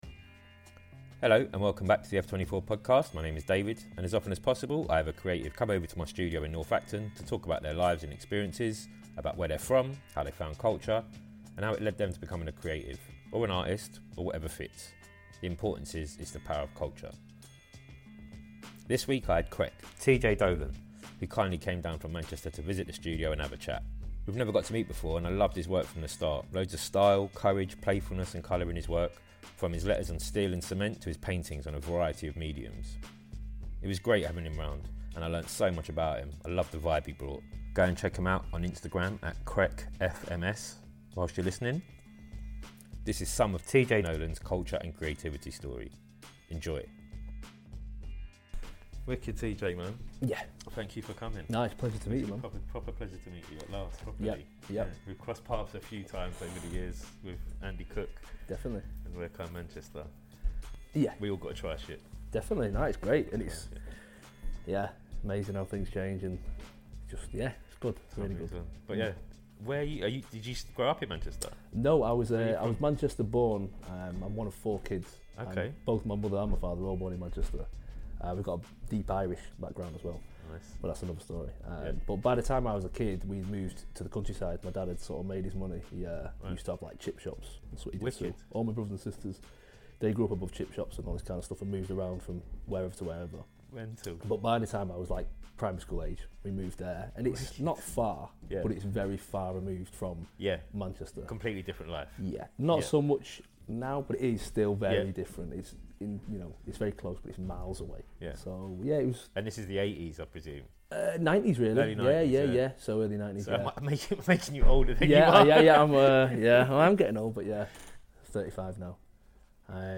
who kindly came down from Manchester to visit the studio and have a chat.